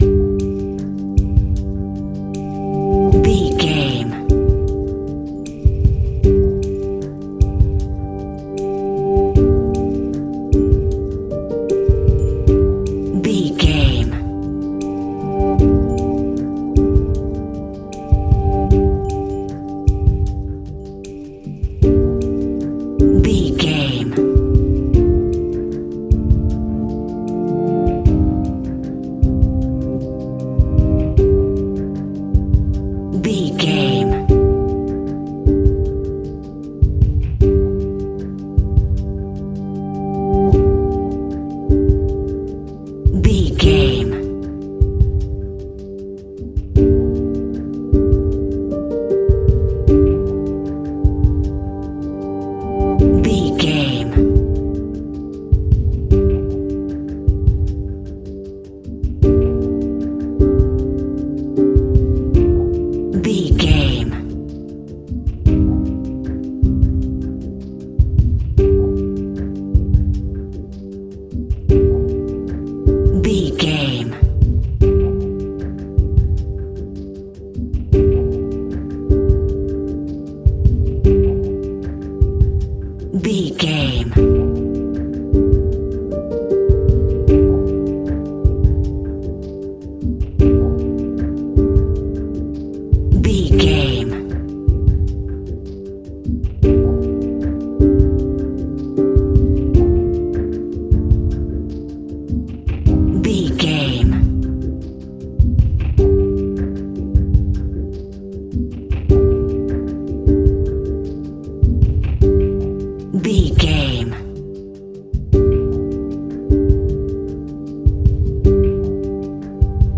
Ionian/Major
tension
suspense
dark
synthesiser